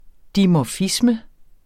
Udtale [ dimɒˈfismə ]